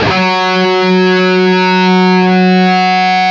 LEAD F#2 CUT.wav